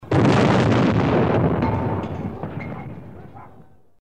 На этой странице собраны реалистичные звуки выстрелов из пушек разной мощности.
Пушка выстрелила и гильза выпала